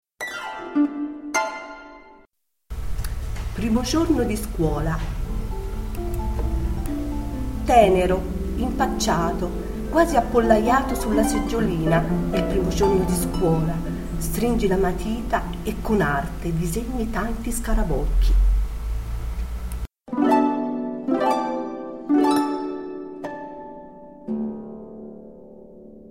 Suoni poetici » Poesie recitate da artisti